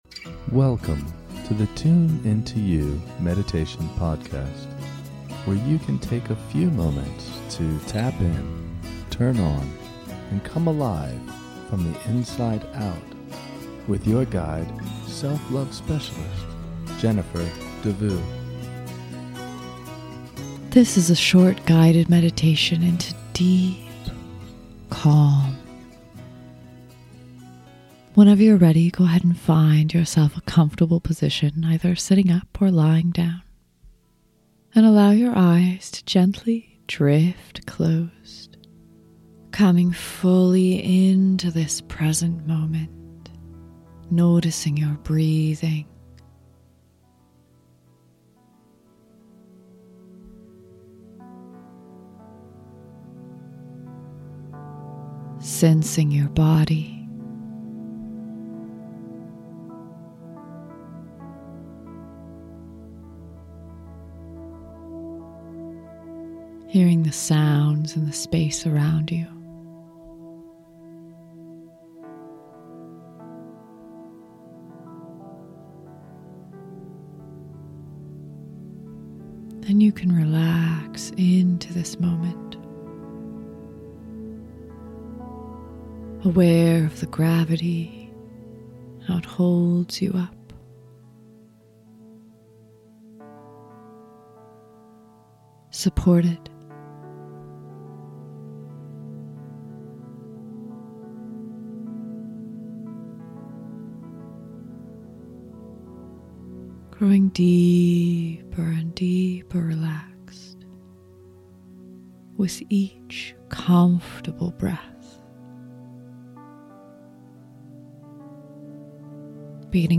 Deep Calm In Less Than 10 Minutes Meditation
How deep will you go with this super short guided meditation for deep calm? This meditation is designed to bring you into an inner space of quiet and calm in less than 10 minutes.